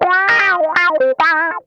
ITCH LICK 10.wav